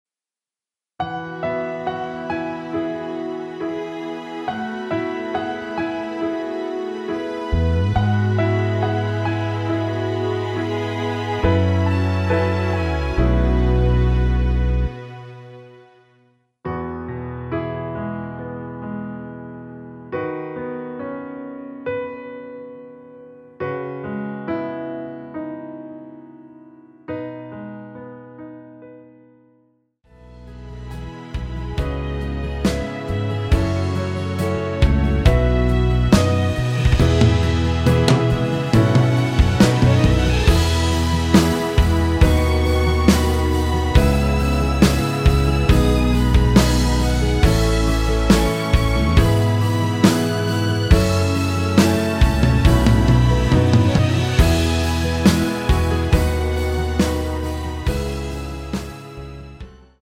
대부분의 여성분이 부르실수 있는 키로 제작 하였습니다.
앞부분30초, 뒷부분30초씩 편집해서 올려 드리고 있습니다.
중간에 음이 끈어지고 다시 나오는 이유는